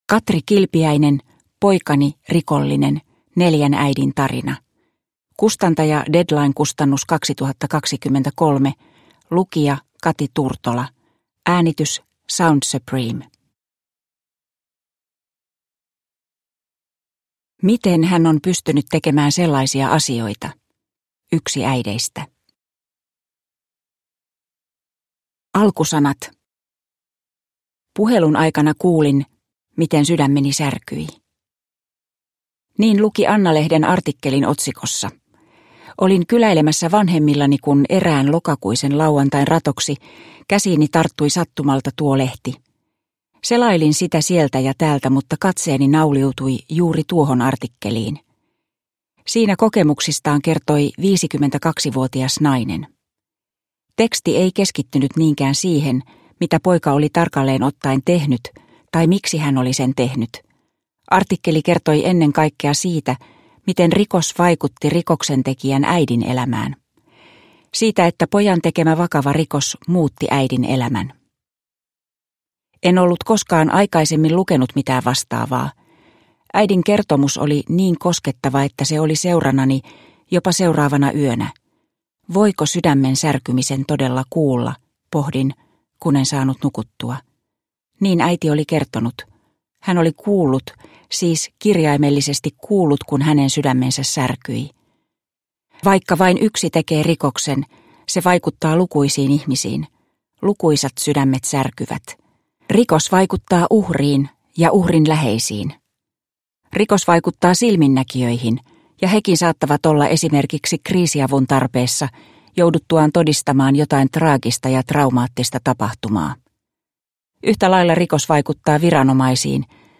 Poikani, rikollinen (ljudbok) av Katri Kilpiäinen